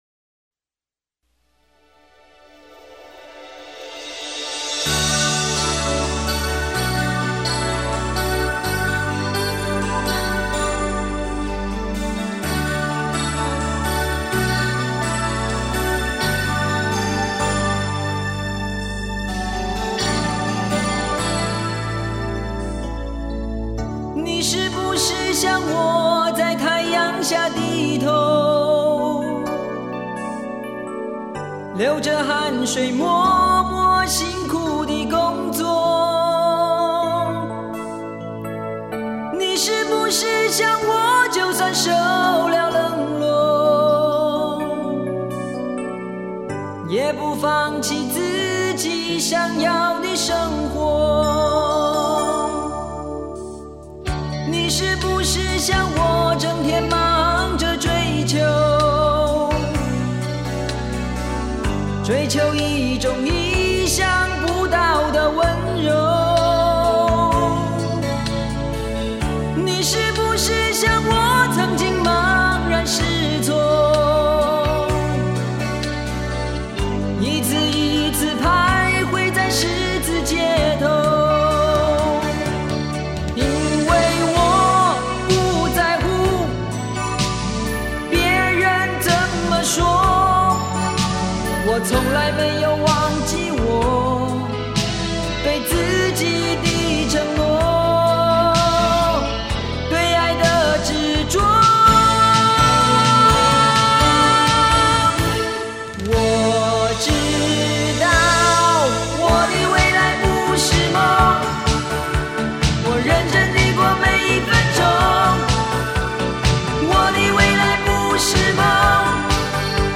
低音质试听 03